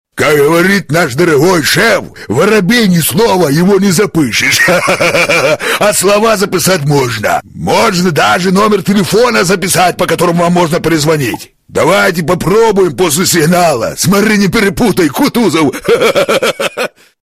Категория: На автоответчик